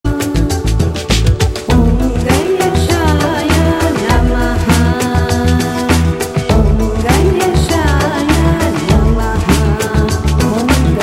• Качество: 128, Stereo
спокойные
инструментальные
восточные
Завораживающие
индийские
мантра
Индийская мантра